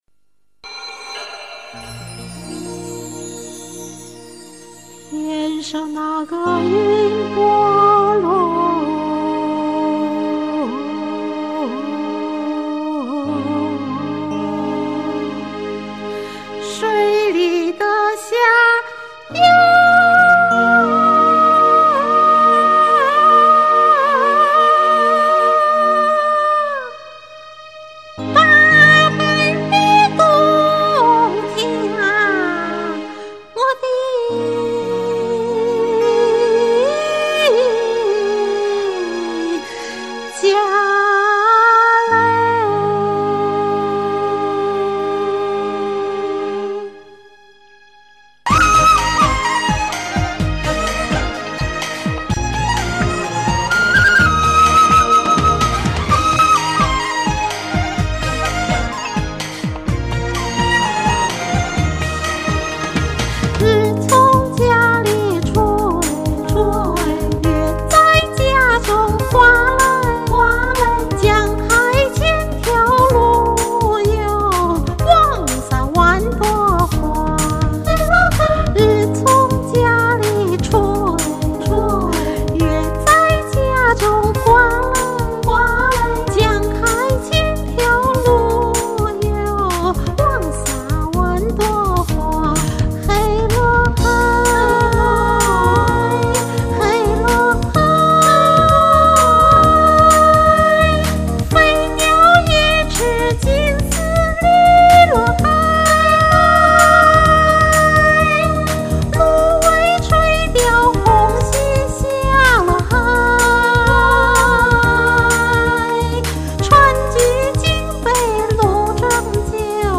湖南新民歌
有人这样形容湖南民歌：“时而高亢，时而婉转，时而诙谐，时而活泼”。
这个伴奏是我以前从网上下载的，只找到这么一版伴奏，杂音很大，比较闹人。这个伴奏还没带伴唱，我只好自己把伴唱也唱了。